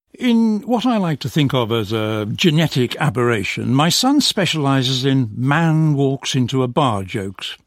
In (272) the nucleus of the final IP falls on bar (186,1 hz), the last word of the clause that constitutes the first part of the compound. Then the frequency falls to 132.9 in jokes.